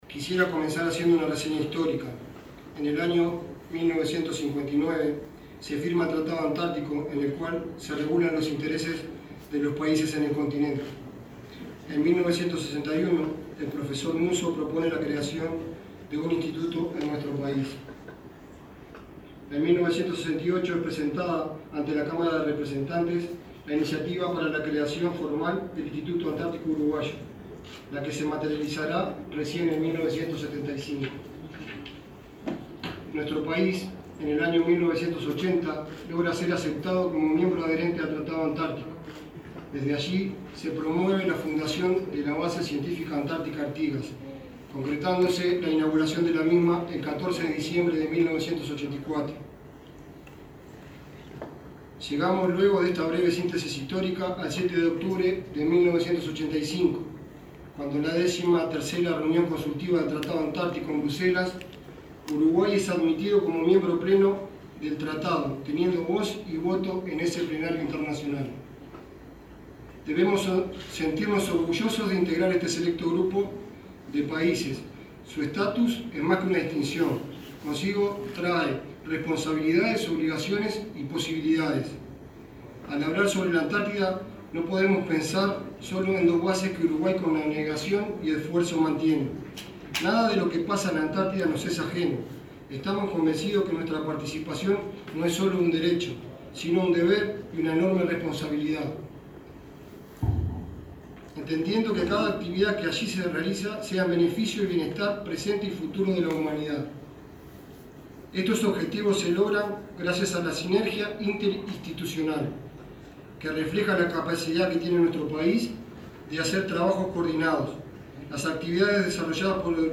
Palabras del nuevo presidente del Instituto Antártico Uruguayo, Fernando Colina
Palabras del nuevo presidente del Instituto Antártico Uruguayo, Fernando Colina 01/07/2021 Compartir Facebook X Copiar enlace WhatsApp LinkedIn El ministro de Defensa Nacional, Javier García, designó a Fernando Colina como nuevo presidente del consejo directivo del Instituto Antártico Uruguayo, en un acto que se desarrolló este jueves 1.° en la sede ministerial.